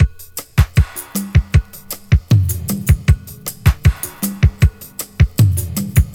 • 78 Bpm Drum Beat G# Key.wav
Free drum groove - kick tuned to the G# note. Loudest frequency: 1767Hz
78-bpm-drum-beat-g-sharp-key-DJC.wav